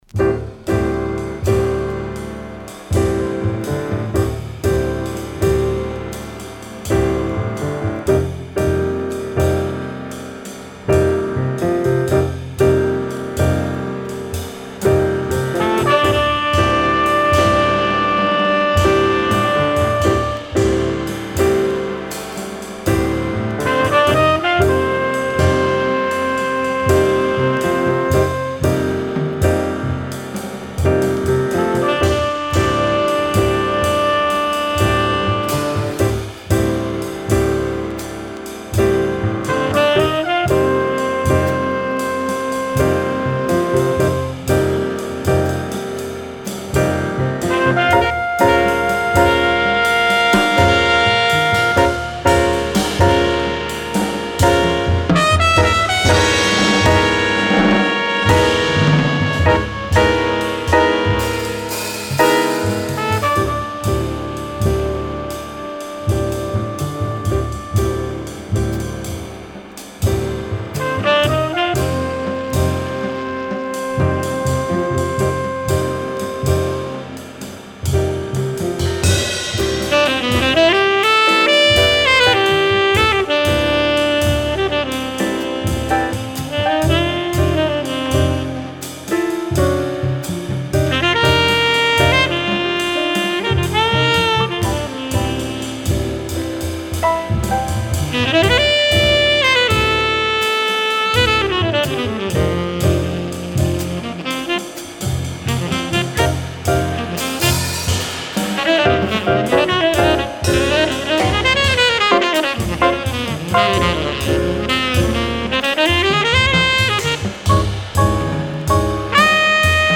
Second “earless New York” mono pressing ca. 1966